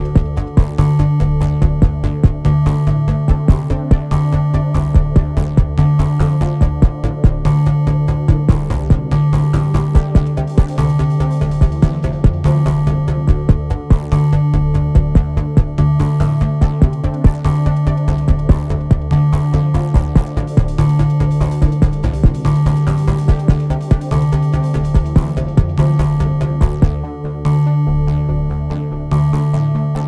oh boy... the bassdrum i get from this puppy... it eats through carpets ..new fav for thuuump